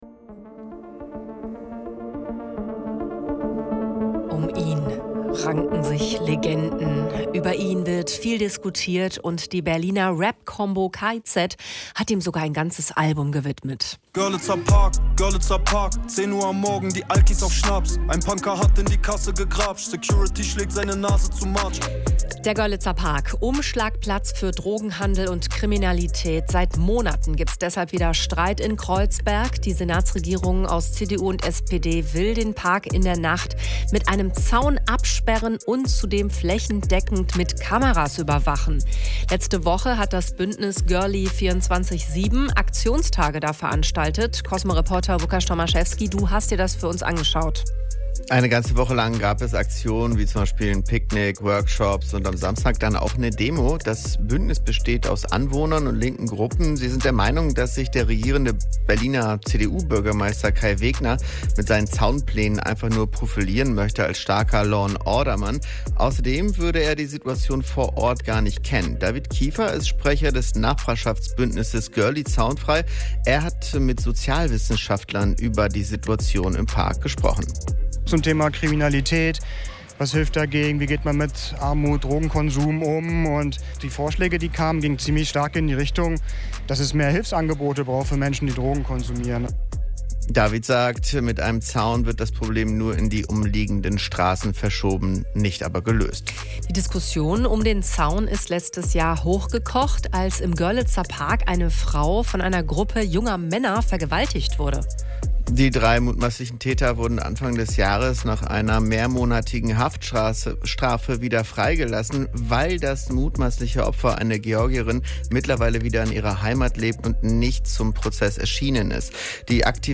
Radiobeitrag zum Zaun um den Görlitzer Park